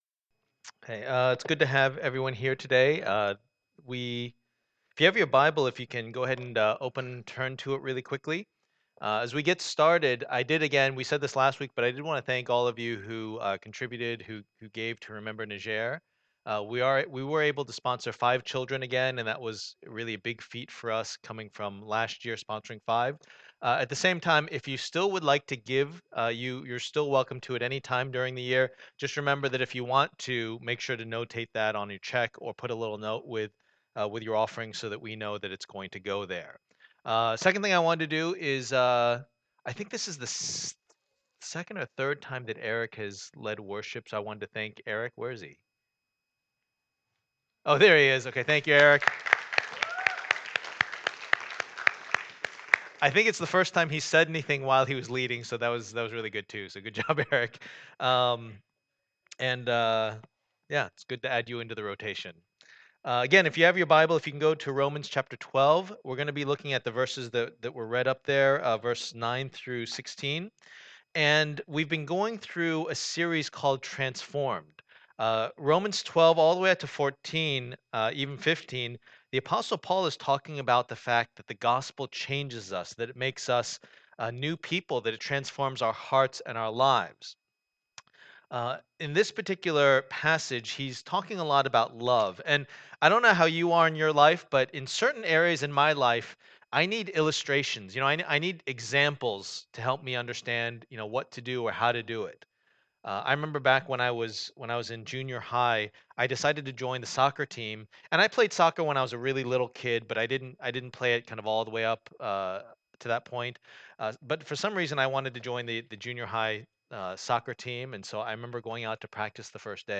Passage: Romans 12:9-16 Service Type: Lord's Day